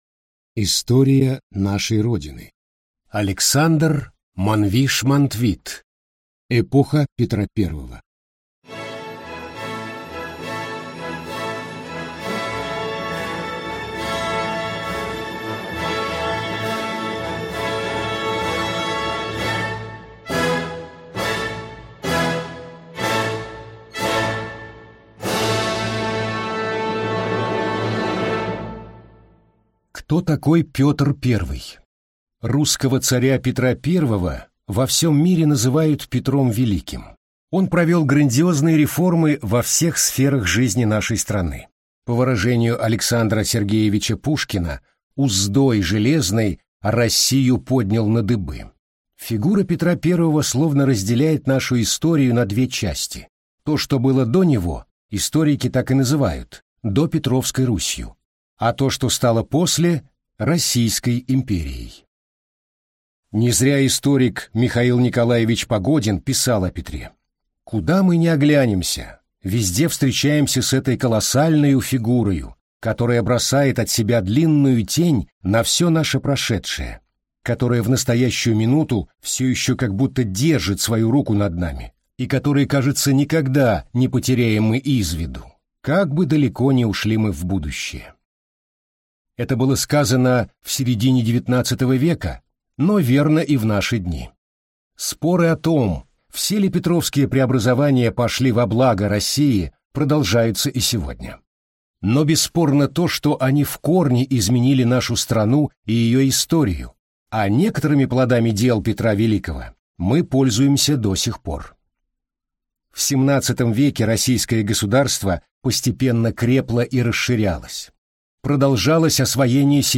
Аудиокнига Эпоха Петра I | Библиотека аудиокниг